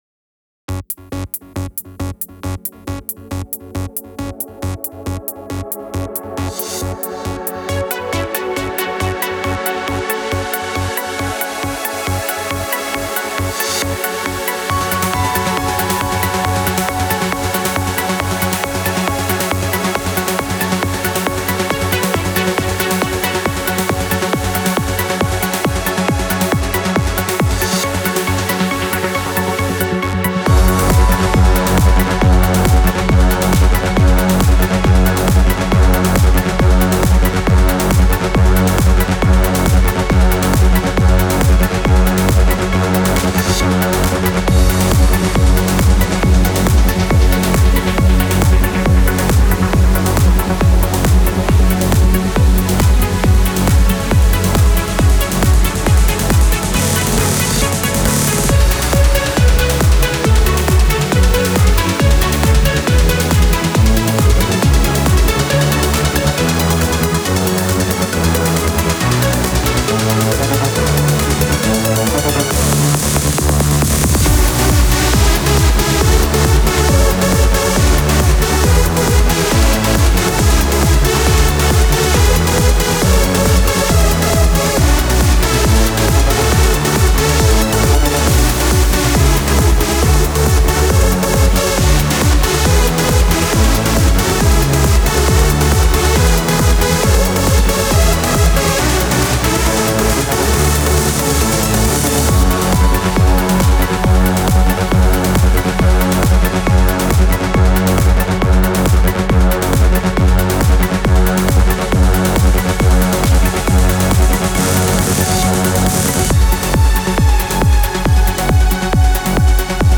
BEST ELECTRO A-F (35)